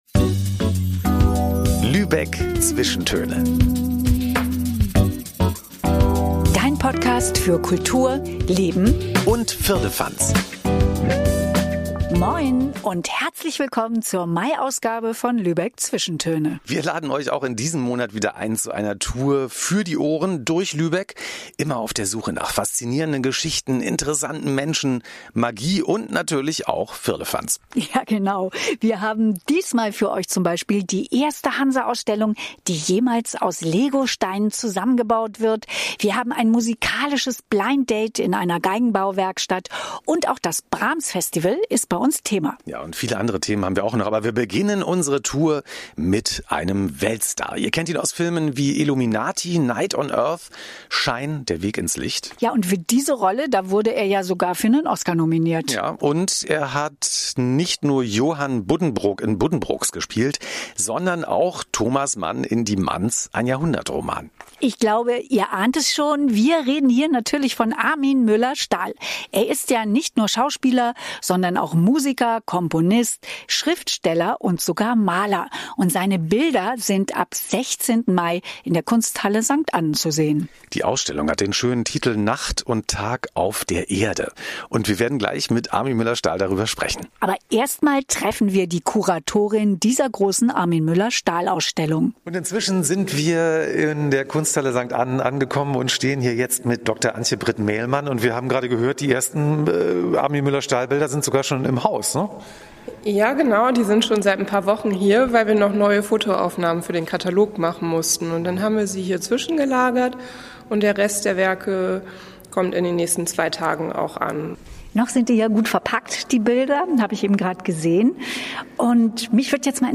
In diesem Monat freuen wir uns auf die große Ausstellung von Armin Mueller-Stahl in der Kunsthalle St. Annen und haben mit dem Künstler bei einem seltenen Interview über seine Malerei gesprochen. Die Lübecker verraten uns ihre Lieblings-Frühlings-Orte. Wir lassen uns vom Enthusiasmus der Brahms-Festival-Macher anstecken und verlieben uns bei einen 1:1-Konzert in einer Geigenbauwerkstatt.